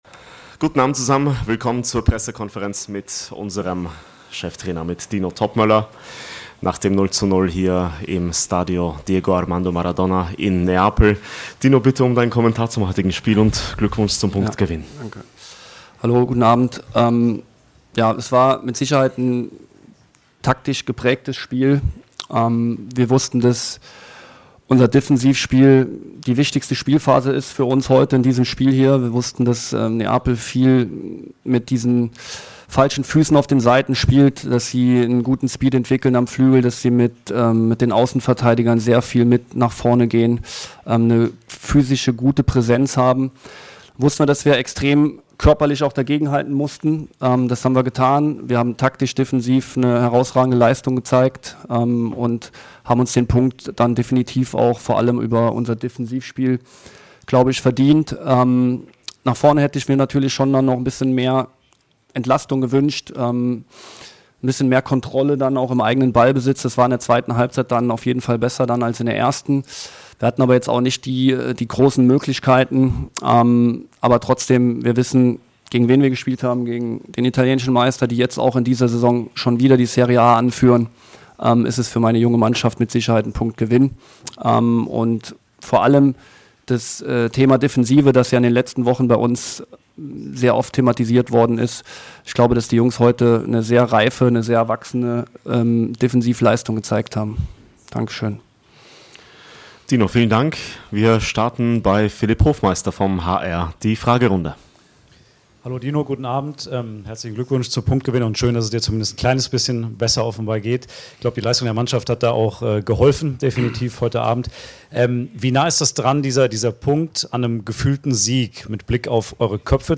Die Pressekonferenz mit Cheftrainer Dino Toppmöller nach dem Champions-League-Spiel gegen Neapel.